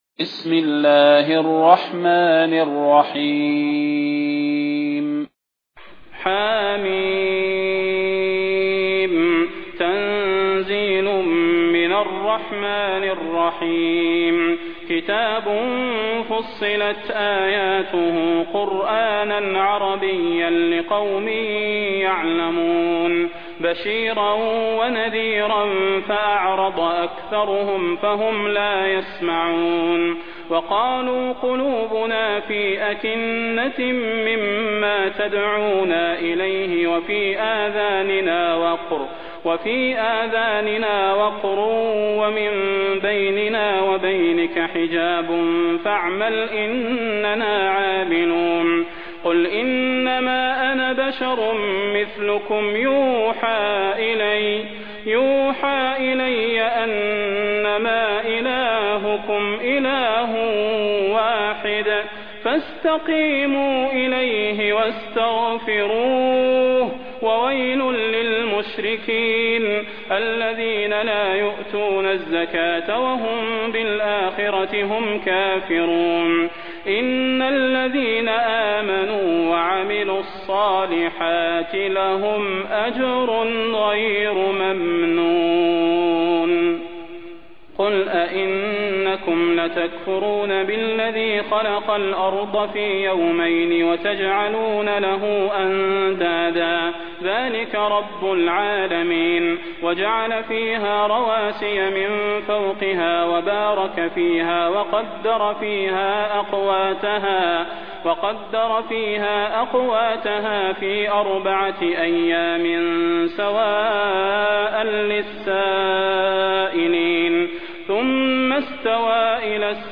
المكان: المسجد النبوي الشيخ: فضيلة الشيخ د. صلاح بن محمد البدير فضيلة الشيخ د. صلاح بن محمد البدير فصلت